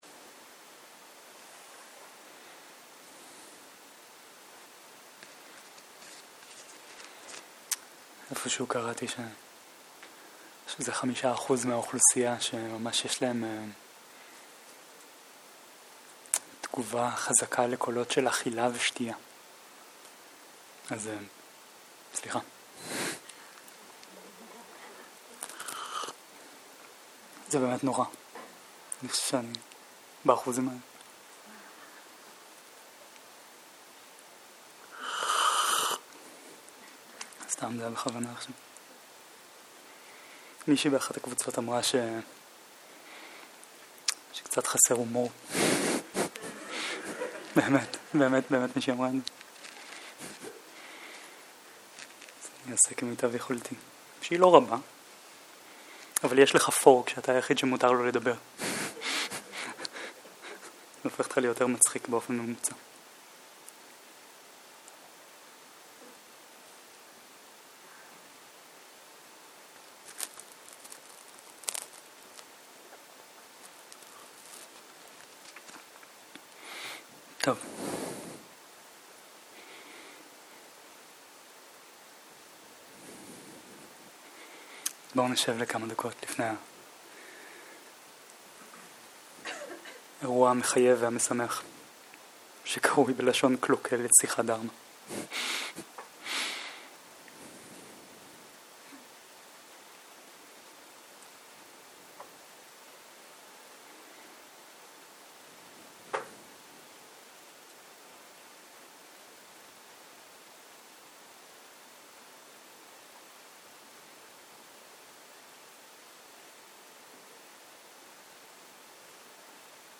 שיחת דהרמה - תשומת לב קשובה